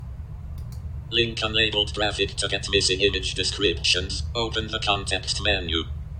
Here’s how a screen reader will read out our email header, if we hadn’t optimized it for accessibility.
That isn’t very helpful, is it?
no-img-alt-text-screen-reader.m4a